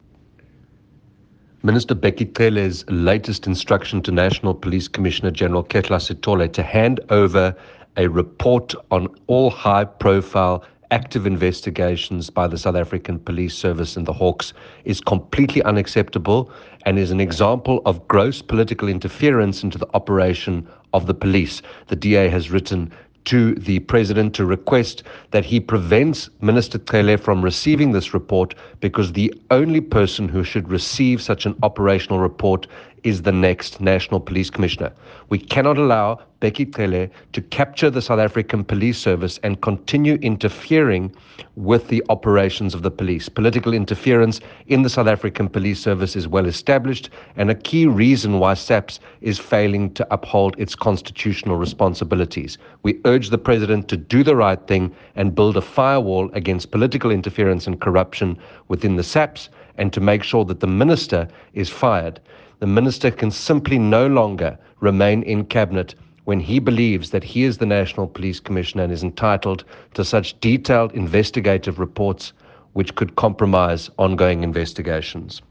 soundbite by Andrew Whitfield MP.